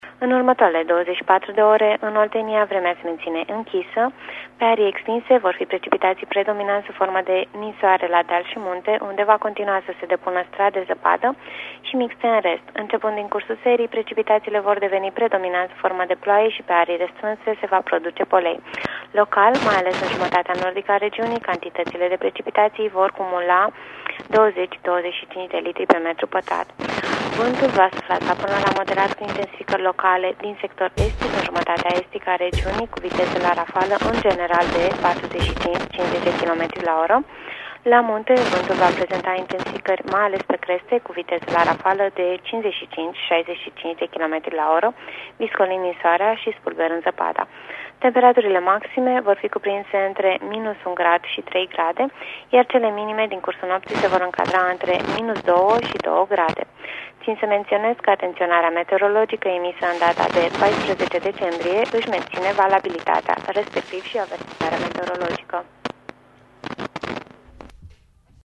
Prognoza meteo 15 decembrie (audio)